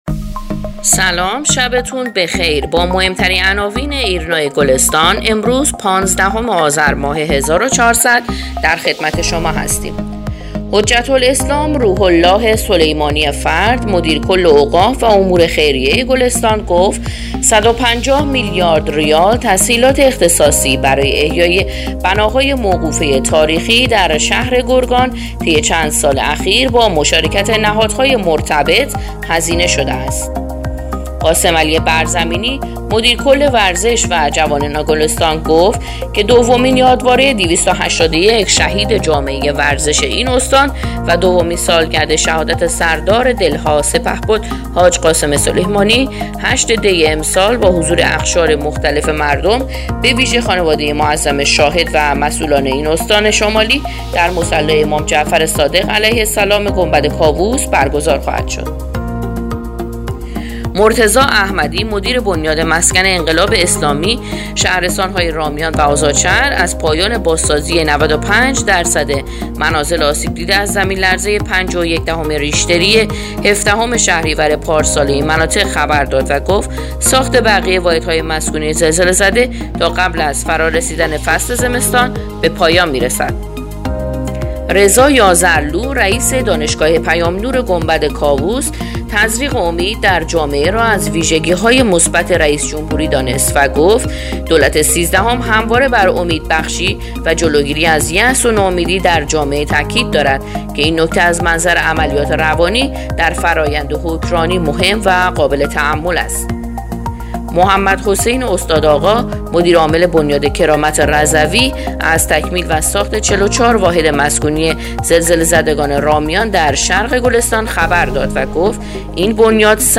پادکست/ اخبار شبانگاهی پانزدهم آذر ایرنا گلستان